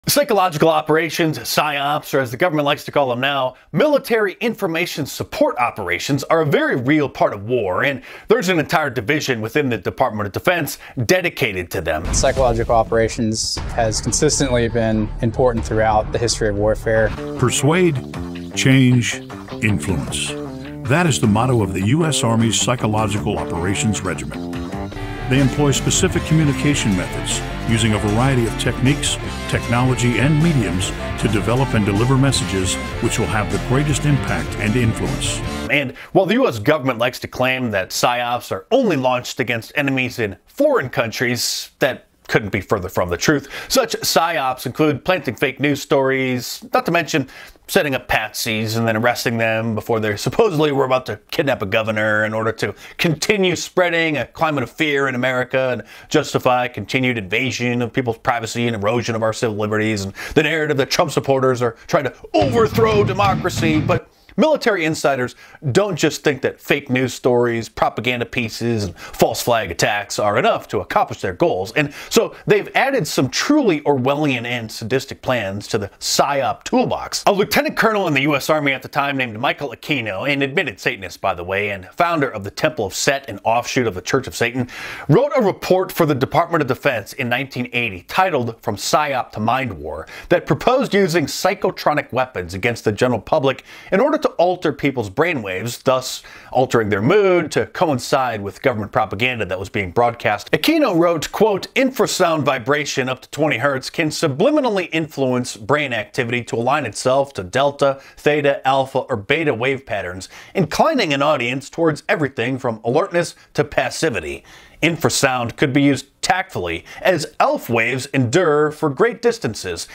PROTEST MUSIC SONG - INCLUDES SPECIAL EFFECTS
2ND SONG STARTS AT 2.40 MIN - WITH AN EXPLOSION!